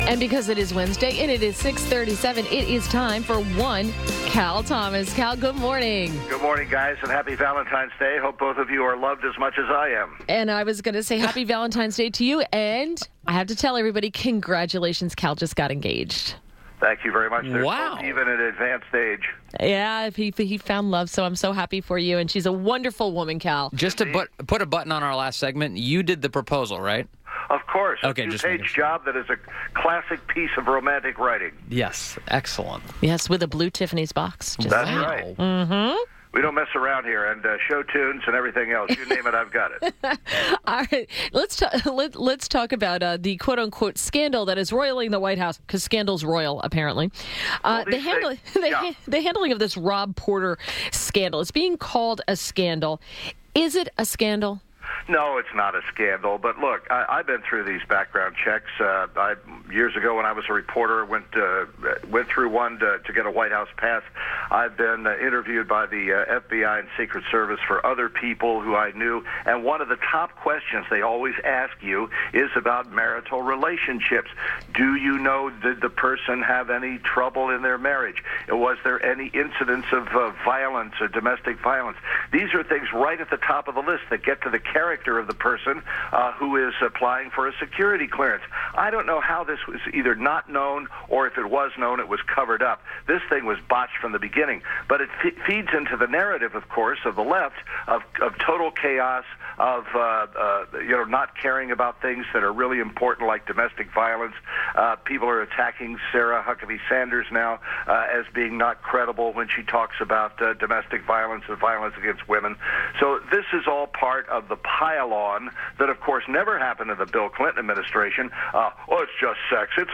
INTERVIEW - CAL THOMAS - Syndicated columnist – discussed Rob Porter and America’s Debt